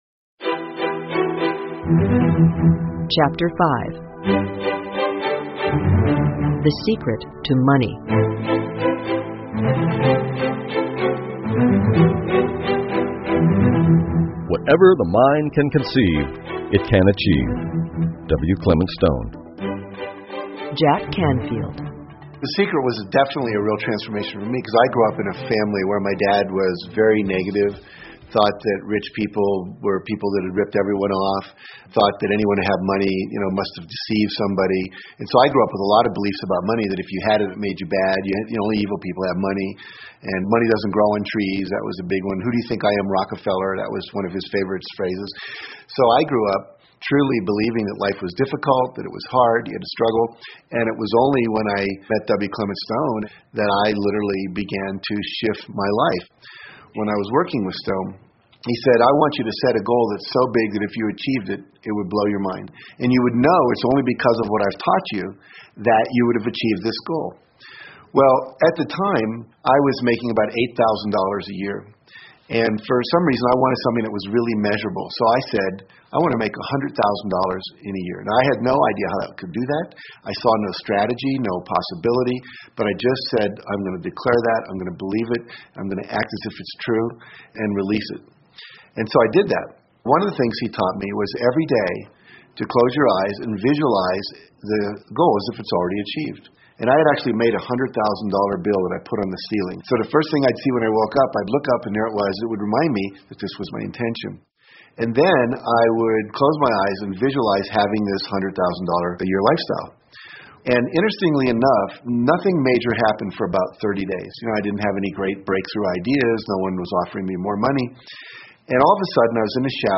英文有声畅销书-秘密 3-01 The Secret To Money 听力文件下载—在线英语听力室